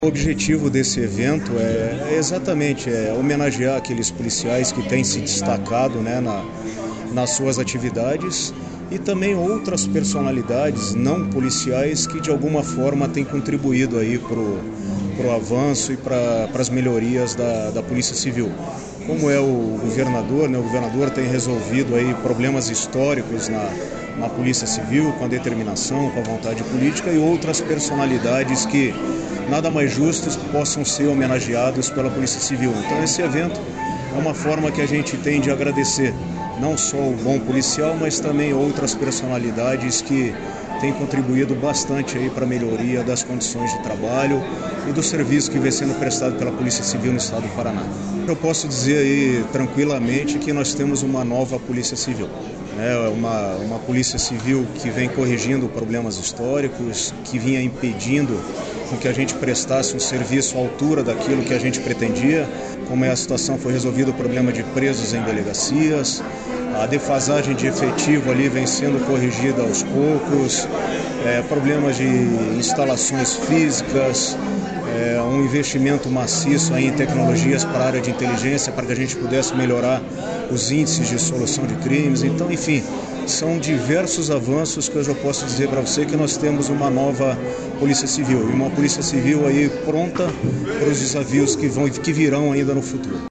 Sonora do delegado-geral da Polícia Civil do Paraná, Silvio Rockembach, sobre entrega de homenagens da Polícia Civil a servidores do órgão e outras lideranças